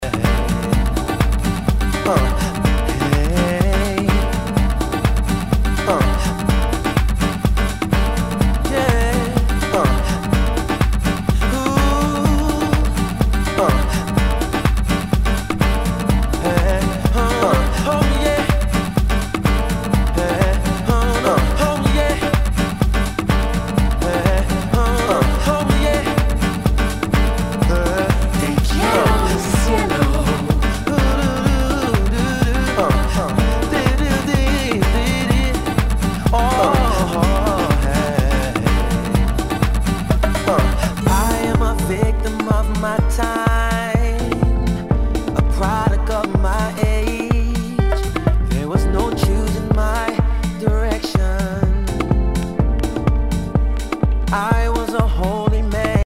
HOUSE/TECHNO/ELECTRO
ディープ・ヴォーカル・ハウス！